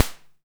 Vintage Snare 03.wav